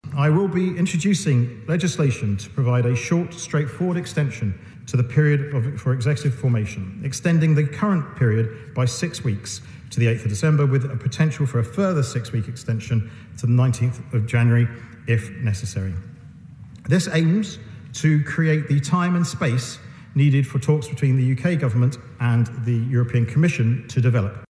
Northern Ireland Secretary Chris Heaton Harris says he’s trying to avoid an election no-one wants……..